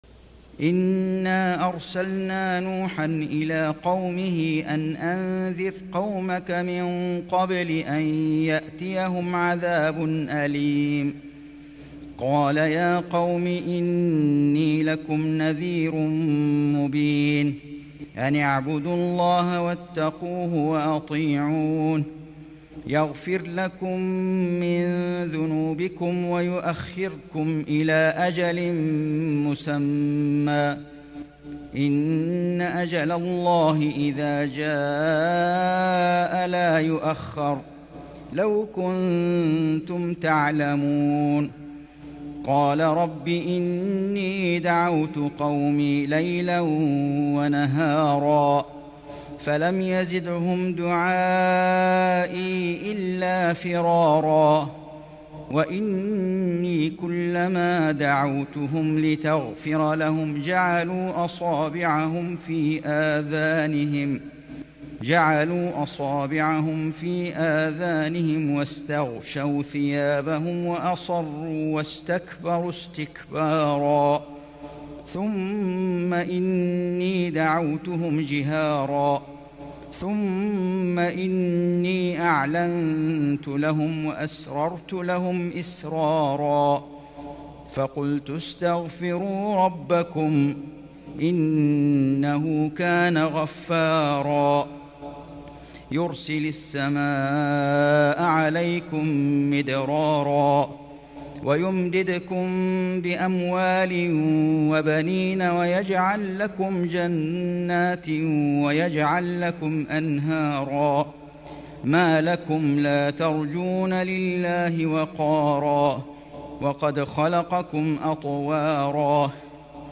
من الحرم المكي